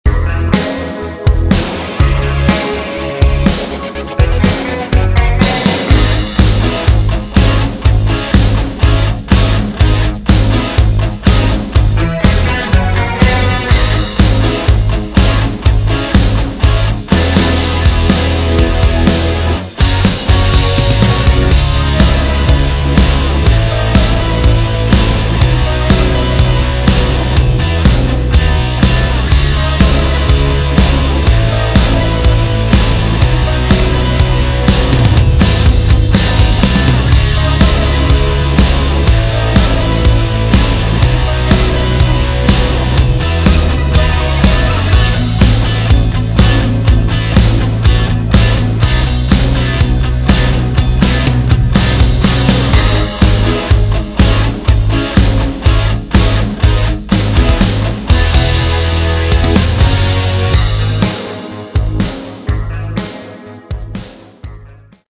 Category: Melodic Rock
guitars, keyboards
lead vocals, guitars
drums
bass
saxophone
backing vocals